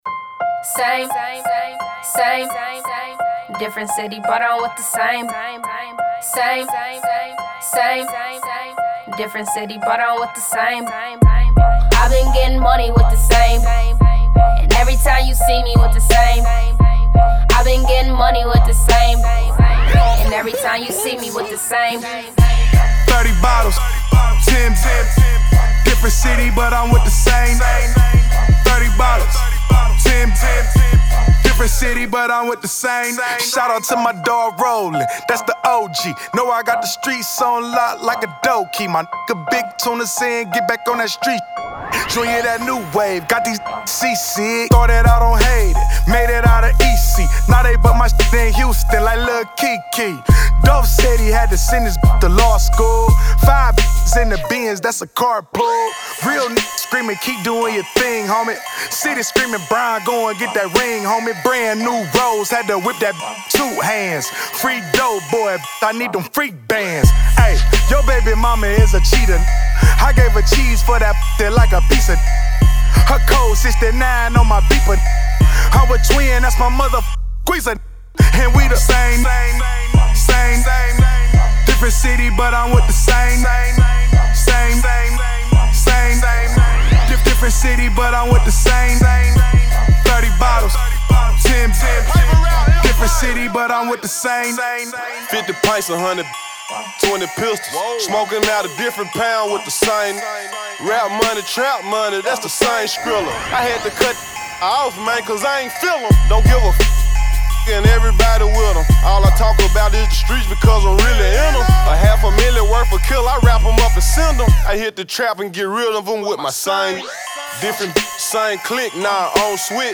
Description : remix